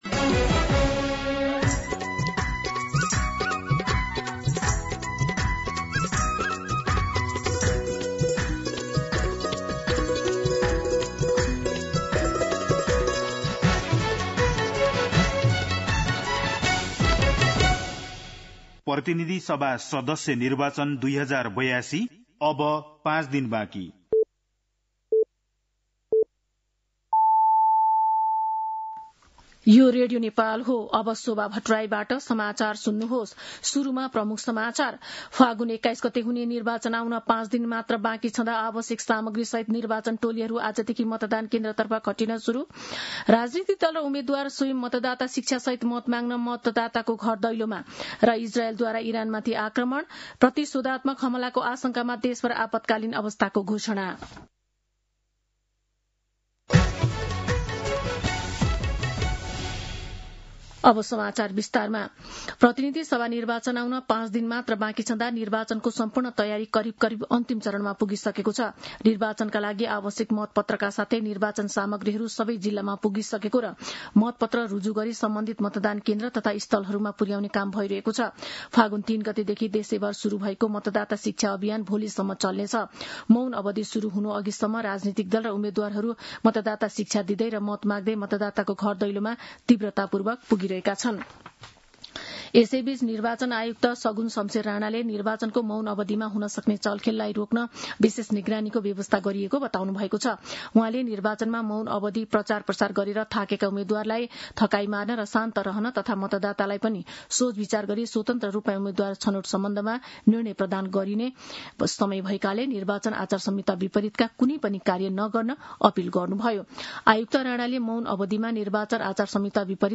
दिउँसो ३ बजेको नेपाली समाचार : १६ फागुन , २०८२
3pm-News-11-16.mp3